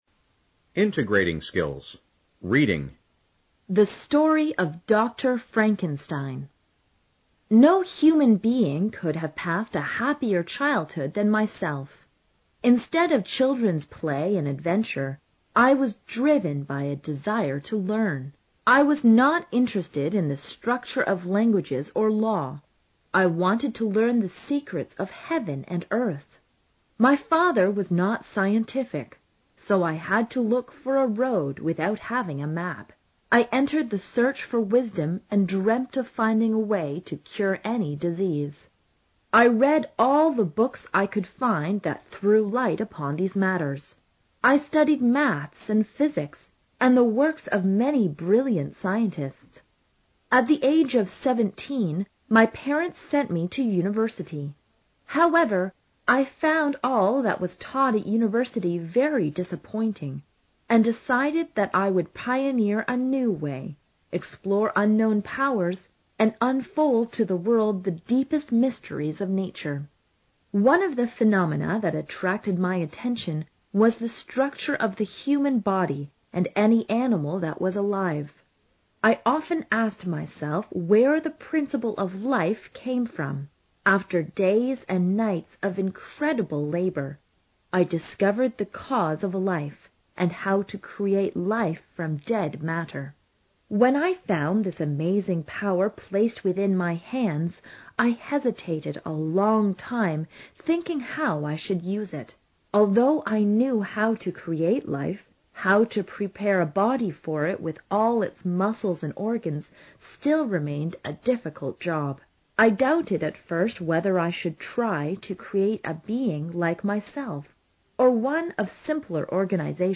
高中英语第二册课本朗读12-a 听力文件下载—在线英语听力室